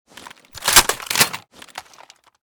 pkm_unjam.ogg